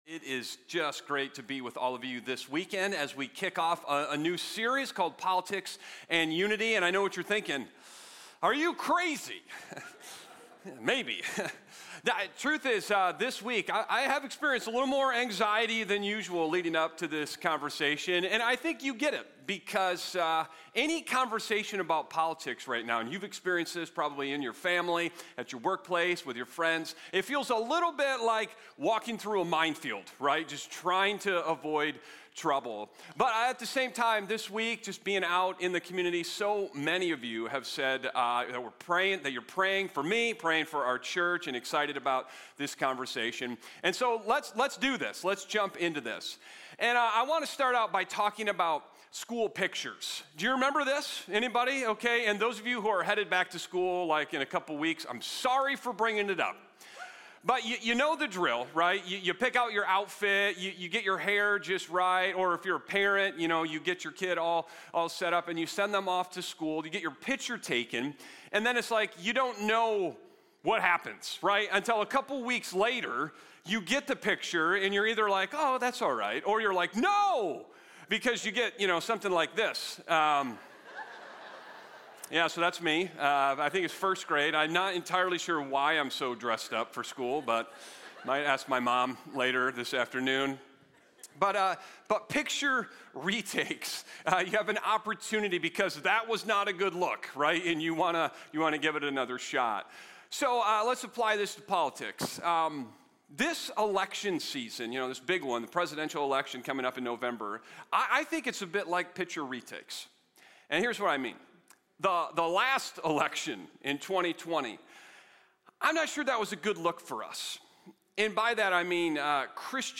Sermon Discussion